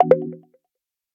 menu-direct-click.ogg